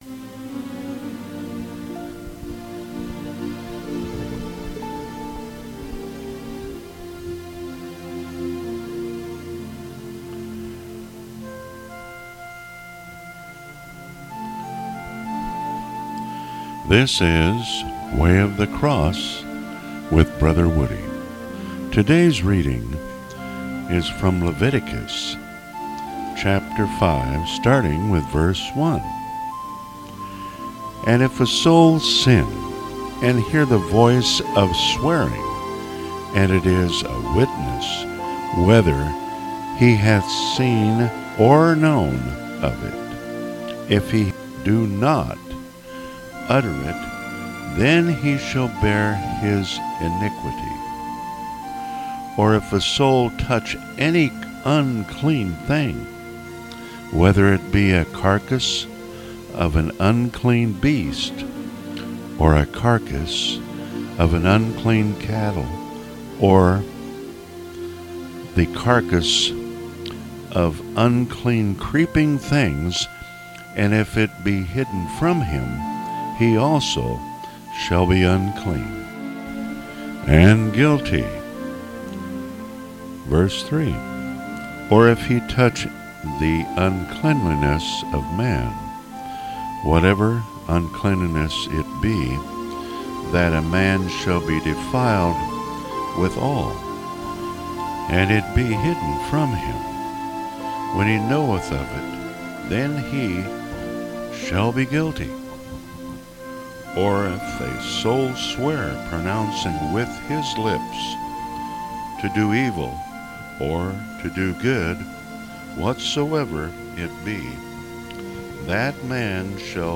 Bible readings